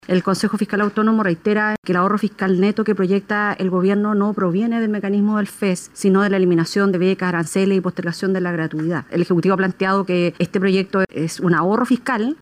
La senadora DC, Yasna Provoste, planteó que el ejecutivo tiene que fijar una posición respecto del análisis que señala que el FES en sí mismo no deja recursos y que son cuestiones externas las que le dan la capacidad de ser un activo.